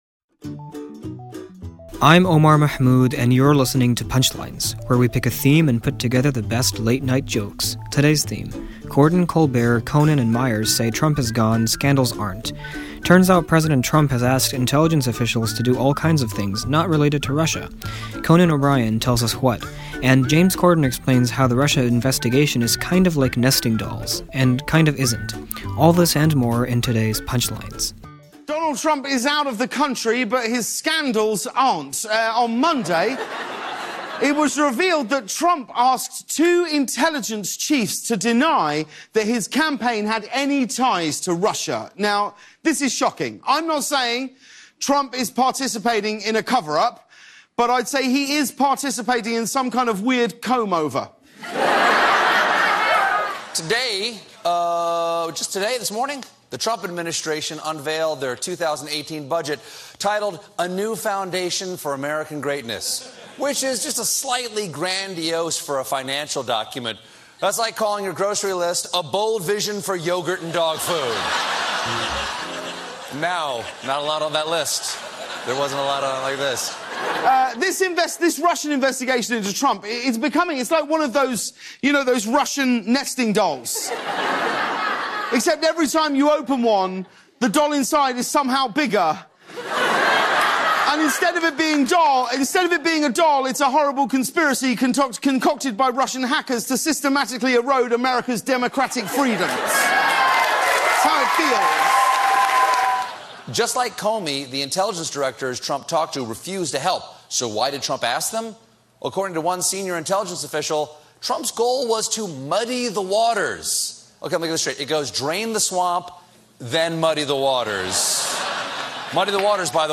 The late-night comics take on the controversies the president has left behind: the budget and Russia.